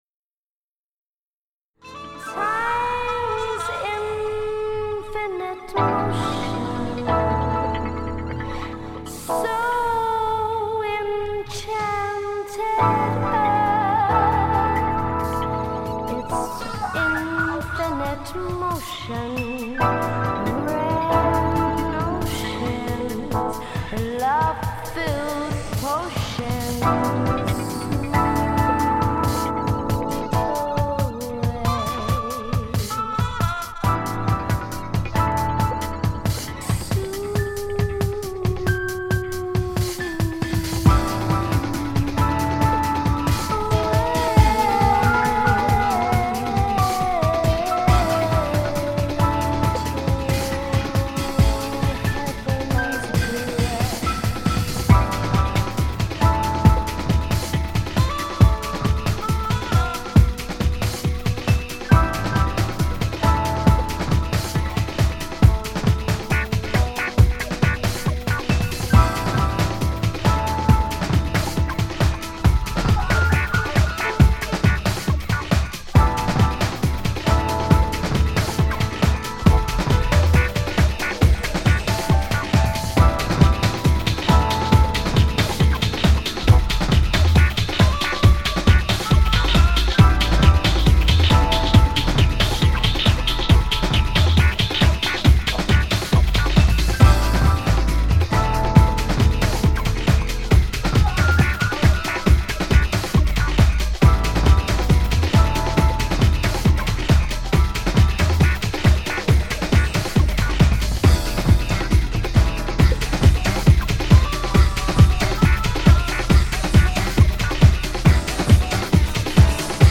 ...this mix from 1998 CD DOWNLOAD: ▼